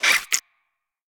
Sfx_creature_babypenguin_hold_equip_above_03.ogg